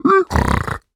Minecraft Version Minecraft Version snapshot Latest Release | Latest Snapshot snapshot / assets / minecraft / sounds / mob / piglin / celebrate2.ogg Compare With Compare With Latest Release | Latest Snapshot
celebrate2.ogg